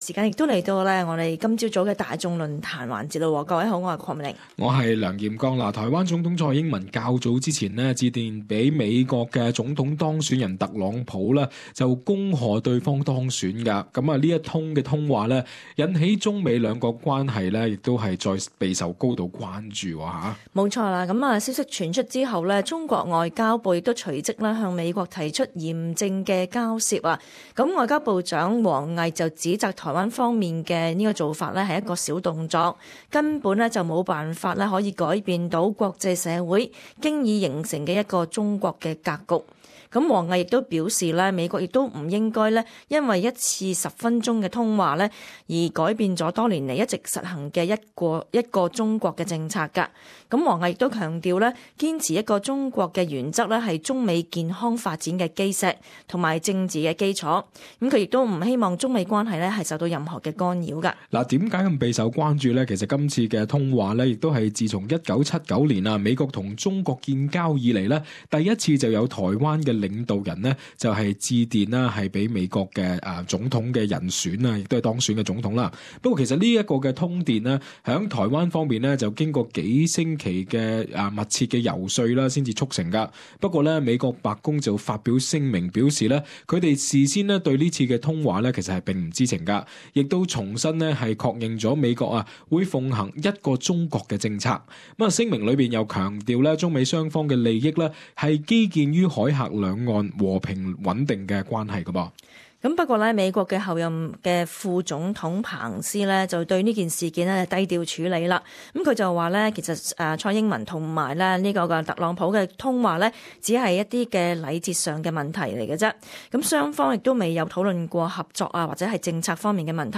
與聽眾討論蔡特通電的影響